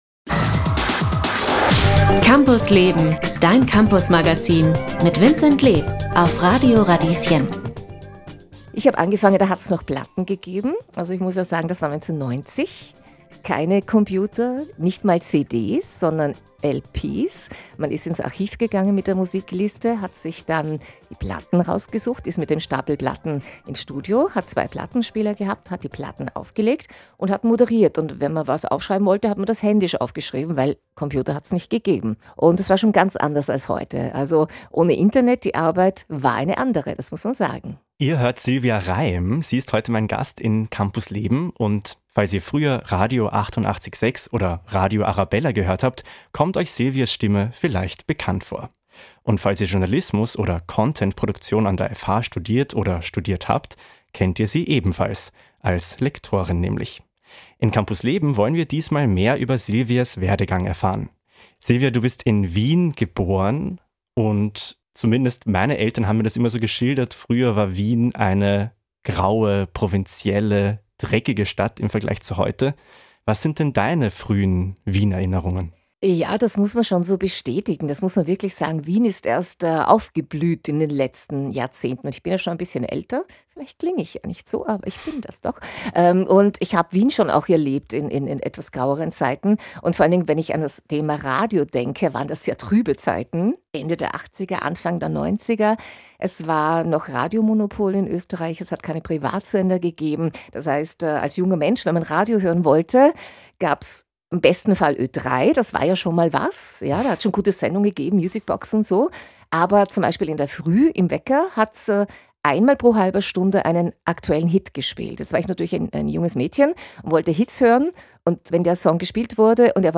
Wusstet ihr, dass sie bei den Piraten-Radiosendern "Antenne Austria" und "Radio CD International" begonnen hat? Diese Podcast-Folge ist ein Ausschnitt aus der Campus Leben-Sendung vom 15. Jänner 2025.